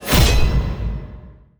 ui_interface_174.wav